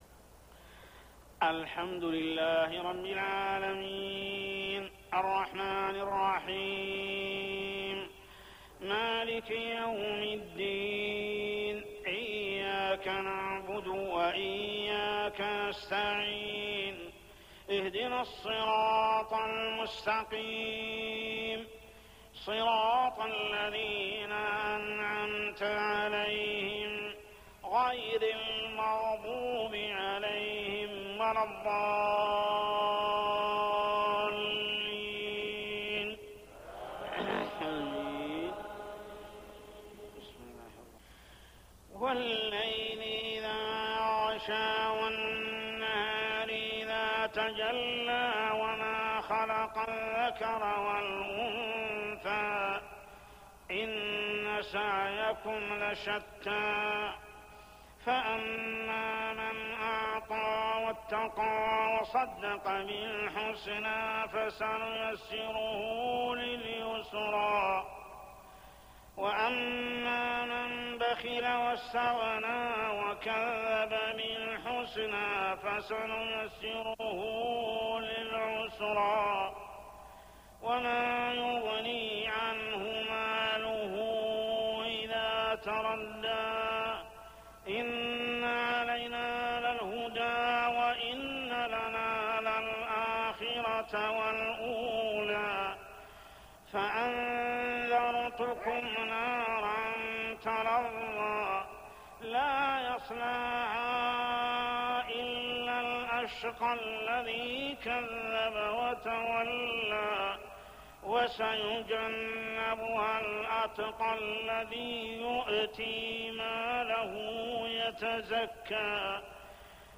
صلاة العشاء 2-9-1415هـ سورتي الليل و الزلزلة كاملة | Isha prayer Surah Al-Layl and Az-Zalzalah > 1415 🕋 > الفروض - تلاوات الحرمين